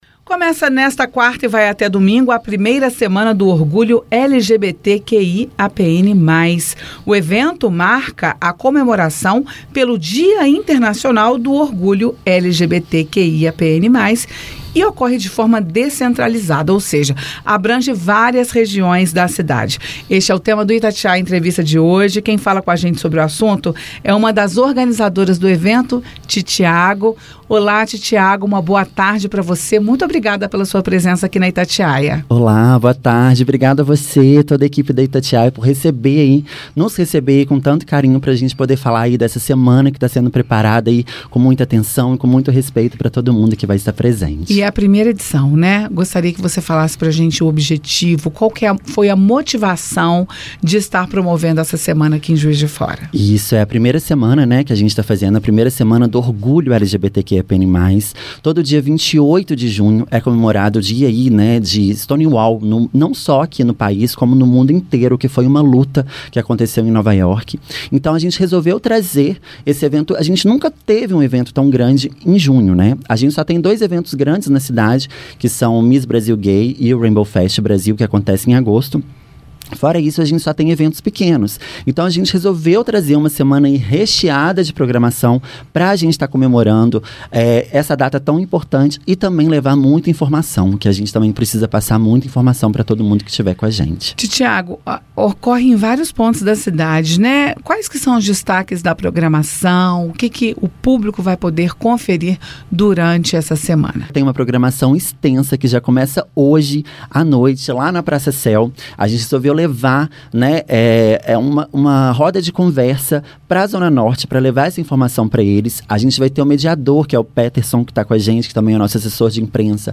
disse uma das organizadoras, a drag queen